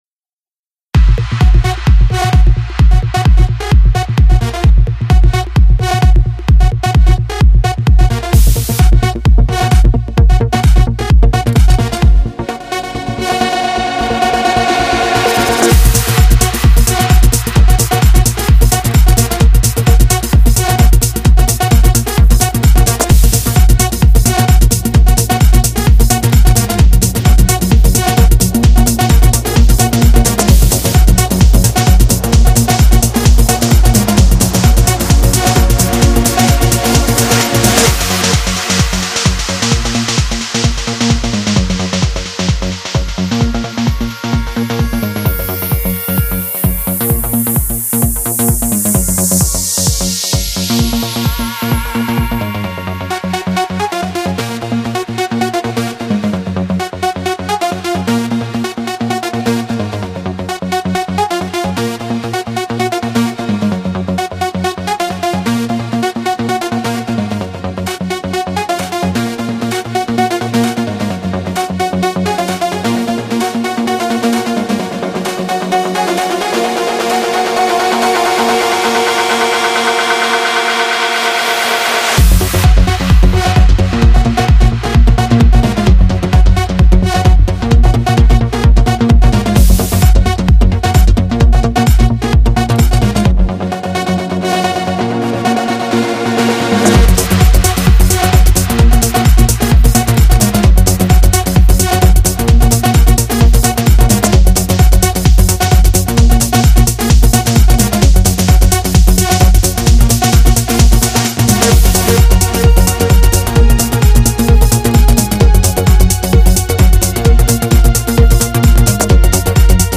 Категория: Trance